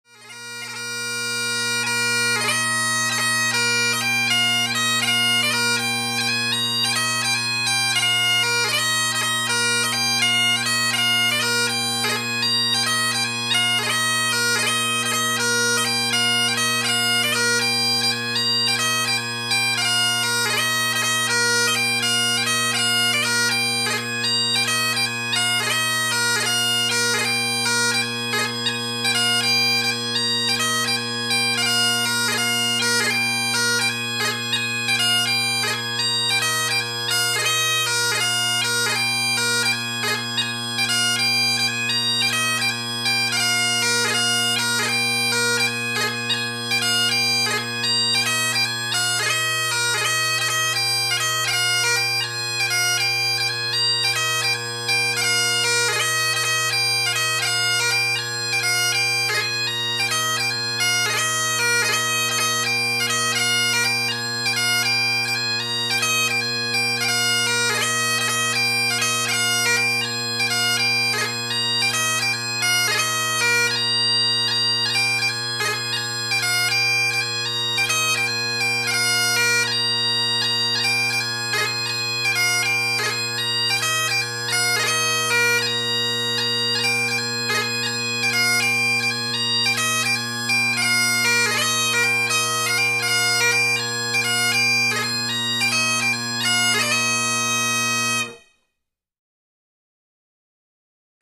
Heritage drones with a Medallist pipe chanter
6/8 march: